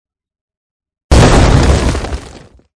mingwang_attack2.mp3